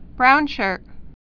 (brounshûrt)